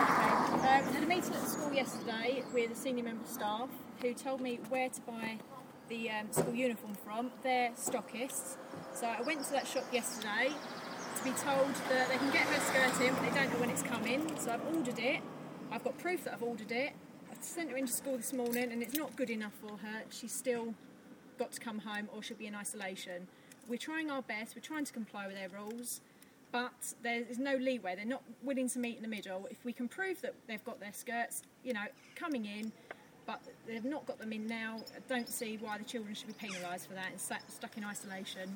One parent speaks to Island Echo outside Ryde Academy